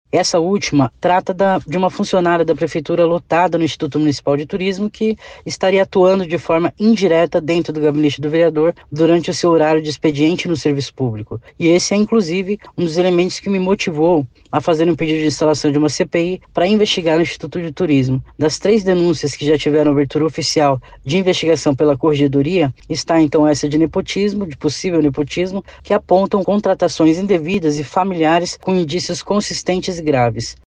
A autora das três representações, vereadora Giorgia Prates – Mandata Preta (PT), falou sobre a acusação mais recente.